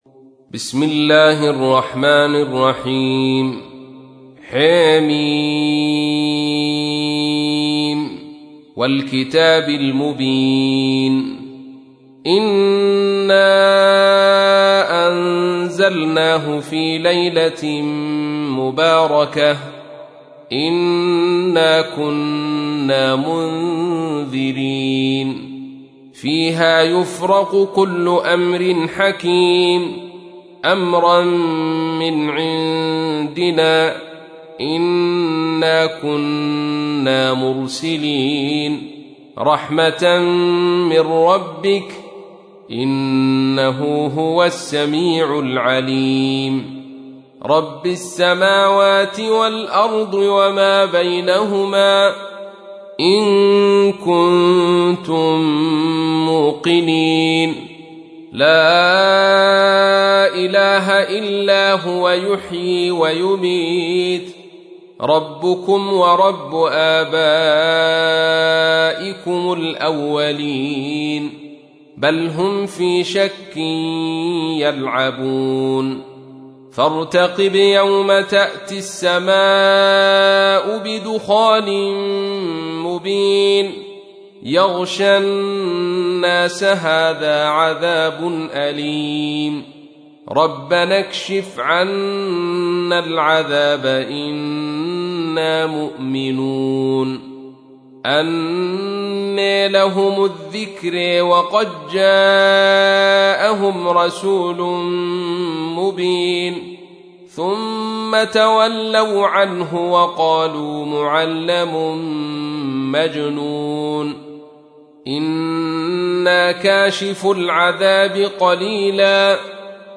تحميل : 44. سورة الدخان / القارئ عبد الرشيد صوفي / القرآن الكريم / موقع يا حسين